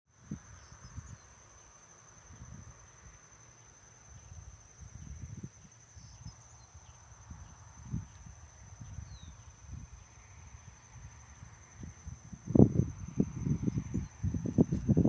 Scientific name: Anthus hellmayri brasilianus
English Name: Hellmayr´s Pipit
Condition: Wild
Certainty: Observed, Recorded vocal